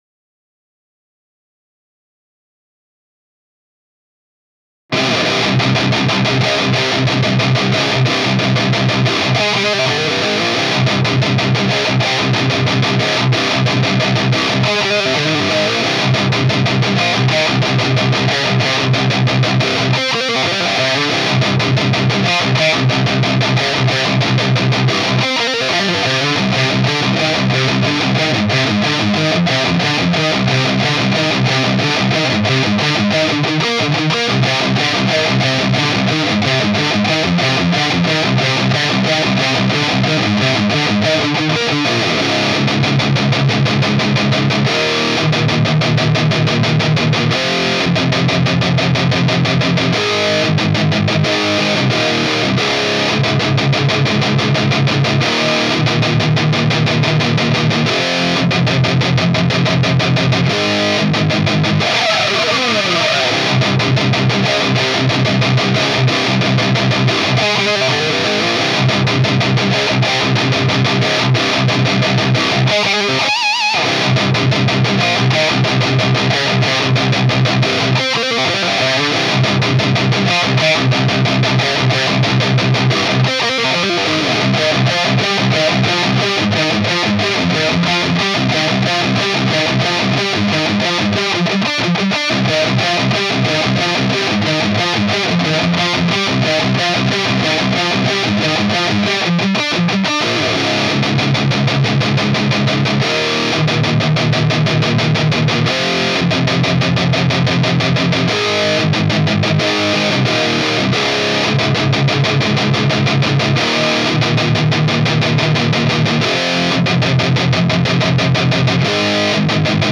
Du coup tu as un son fyzz pas mal et tu penses qu'il y a plus de gain que ce qu'il y a en réalité.
REAMP GT L1 B57.wav